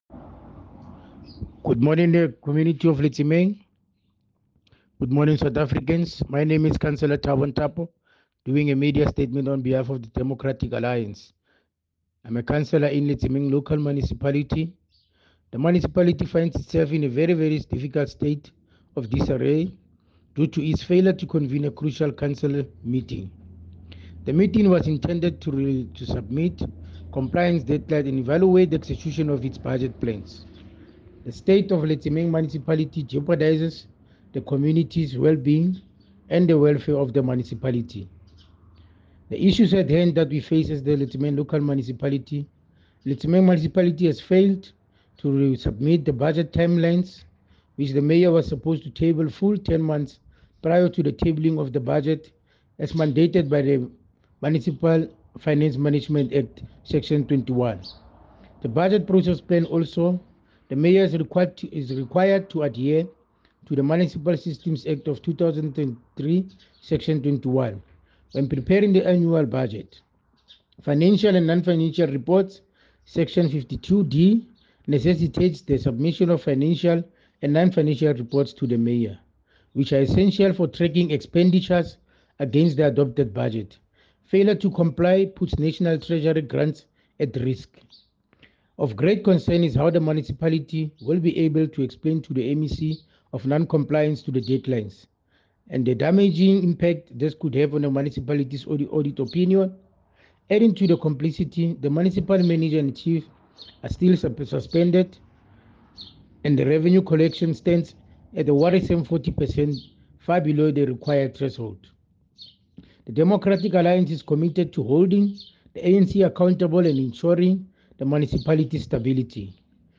English soundbite by Cllr Thabo Nthapo and